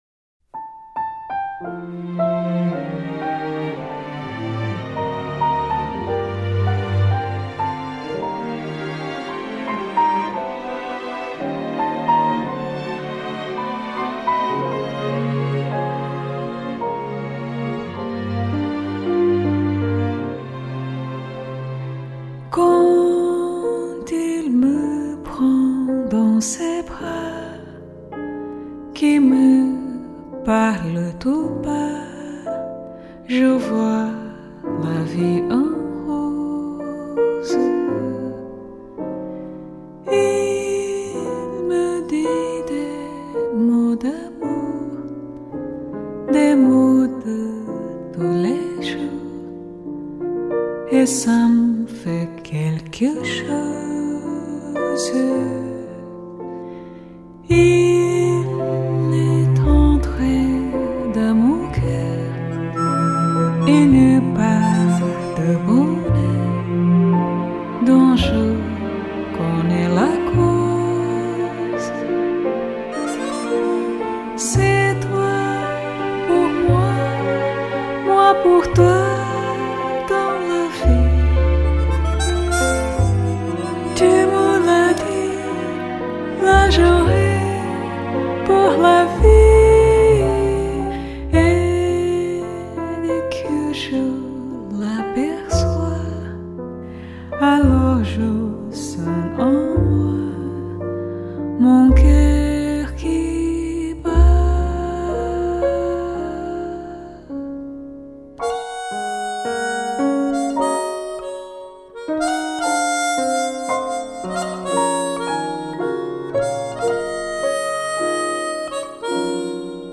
而本张专辑内12首中的11首曲子，都交织着华丽的弦乐编制格局。